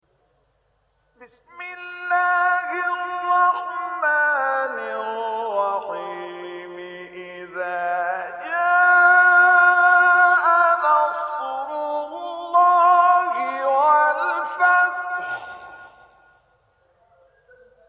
گروه شبکه اجتماعی: مقاطعی صوتی از تلاوت قاریان برجسته مصری ارائه می‌شود.
مقطعی از عبدالفتاح شعشاعی در مقام عجم